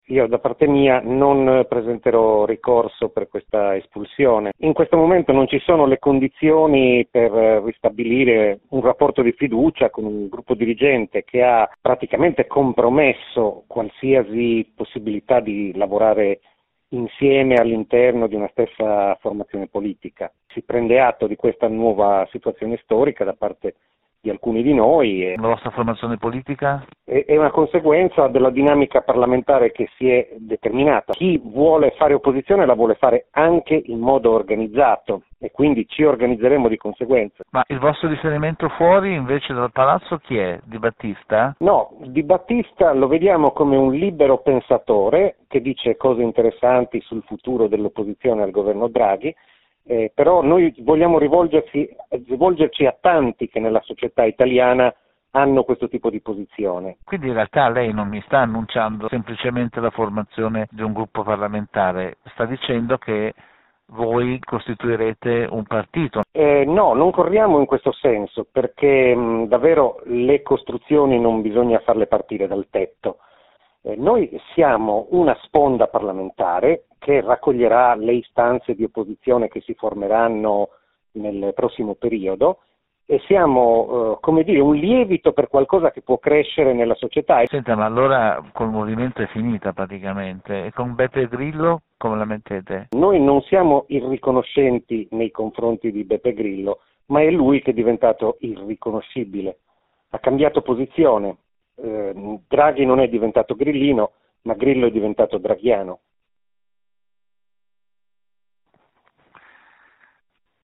Anche tra gli espulsi dal movimento 5 stelle ci sono divisioni. Questa mattina ai microfoni di radio popolare il deputato Pino Cabras ha spiegato che la strada è quella di creare una nuova forza politica.